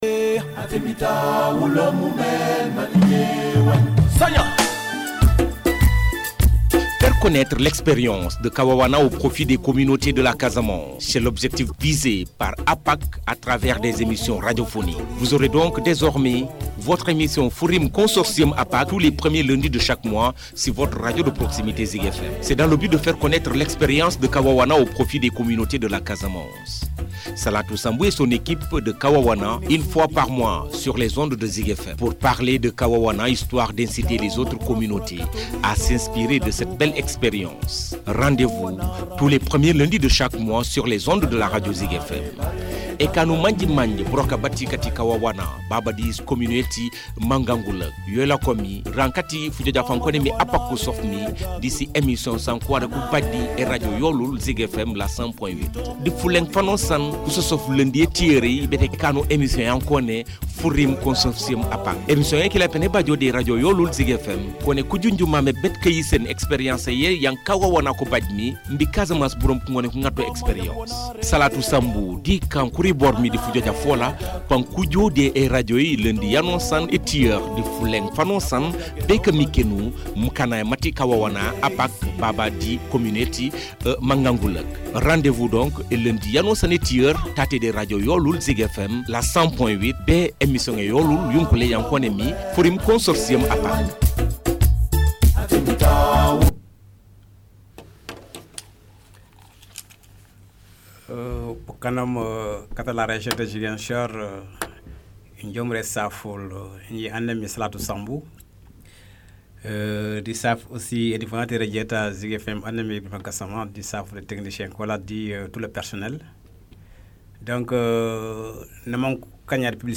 The programme included a live “question and answer” session, to diffuse information and support an open dialogue on ICCAs among fishermen and the public at large. The series has been most successful, promoting better knowledge about what an ICCA is, in general, but also what are the specific rules of KAWAWANA as a local ICCA in the marine and coastal environment, and why those rules are useful.